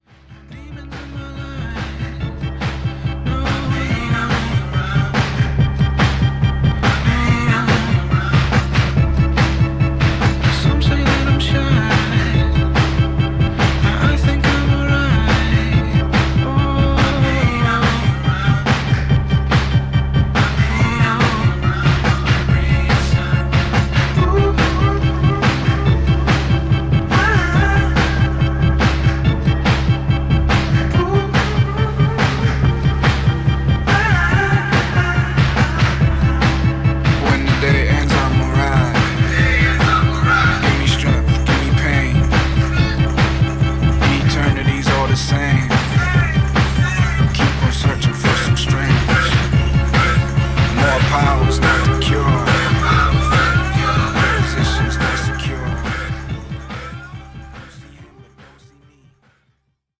スコットランドのプログレッシブ・ヒップホップ・グループ